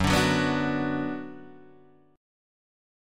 Gbsus4#5 chord